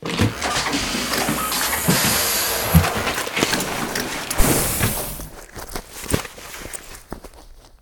suitremove.ogg